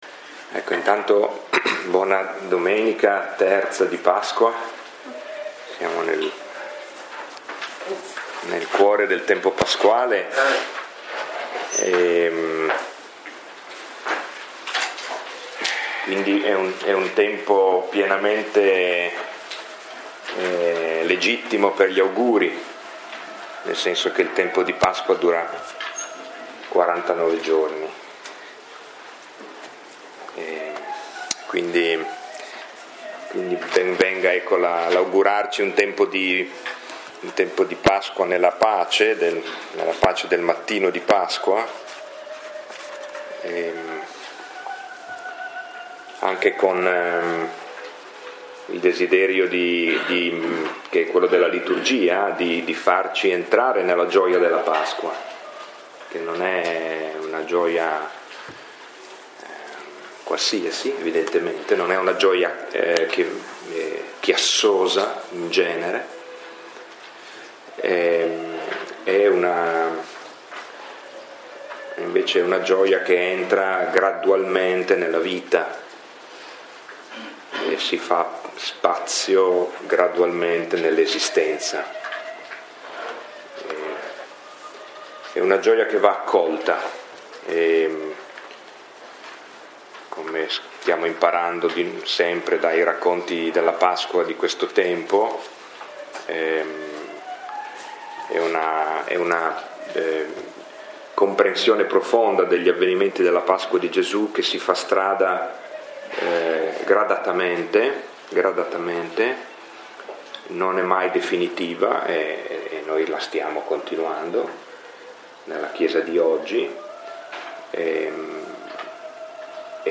Lectio 7 – 15 aprile 2018